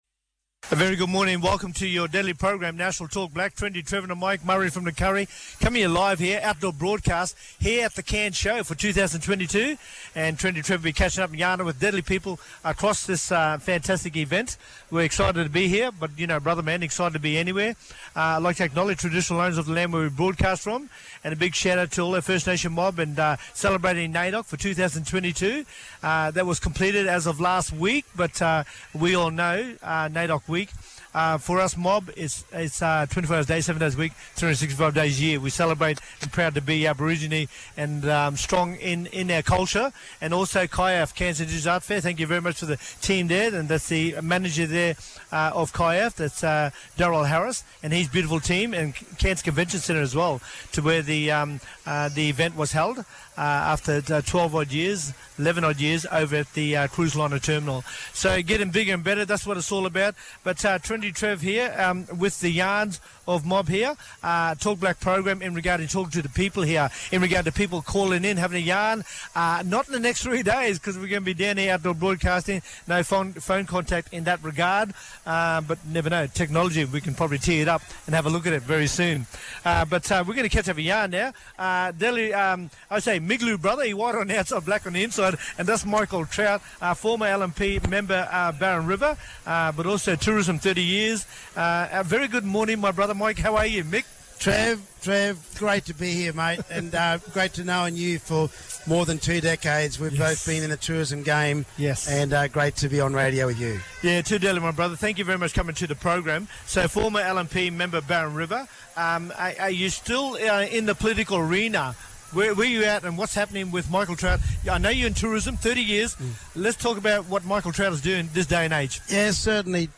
Listen back on our Talkblack Program live from the Cairns Show!